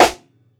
Rimz_live.wav